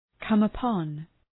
come-upon.mp3